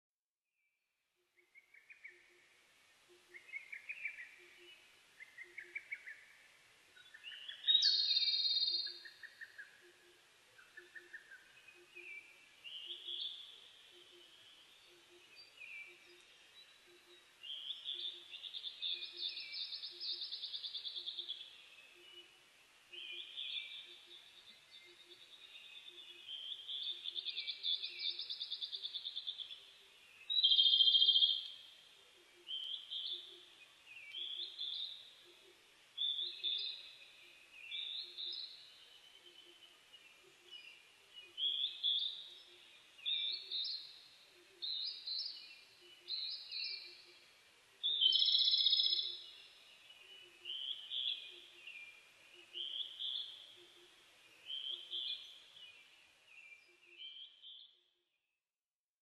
コマドリ　Erithacus akahigeツグミ科
日光市稲荷川上流　alt=1190m  HiFi --------------
MPEG Audio Layer3 FILE  Rec.: MARANTZ PMD670
Mic.: audio-technica AT825
他の自然音：　 アカハラ・ホトトギス・ツツドリ・ジュウイチ